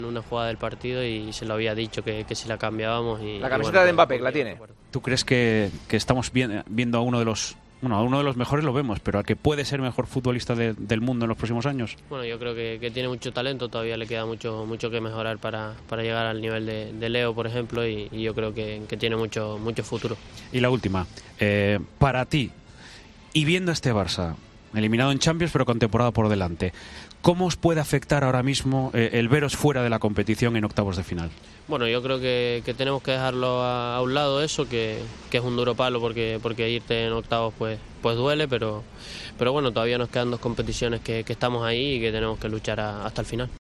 El canario ha atendido a los micrófonos de Movistar tras el empate del Barcelona en París y ha confesado que, tras una jugada, le pidió la camiseta a Mbappé.